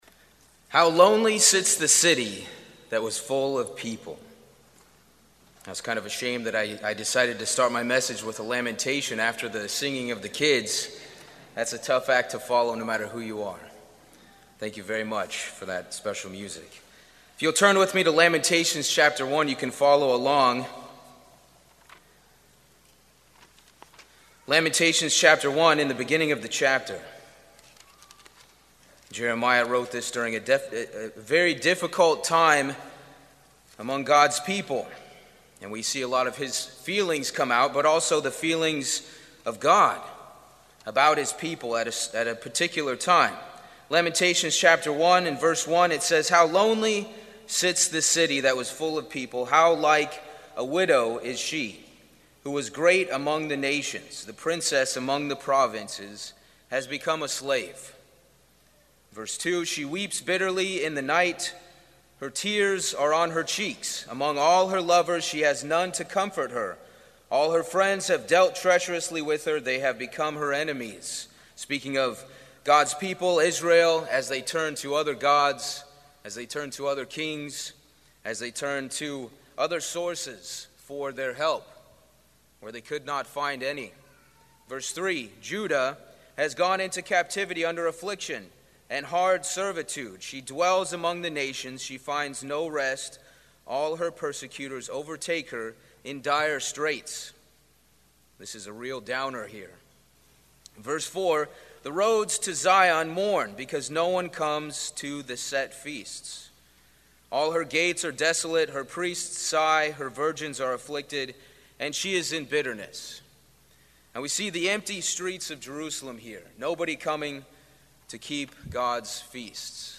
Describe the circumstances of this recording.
This sermon was given at the Ocean City, Maryland 2023 Feast site.